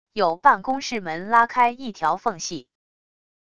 有办公室门拉开一条缝隙wav音频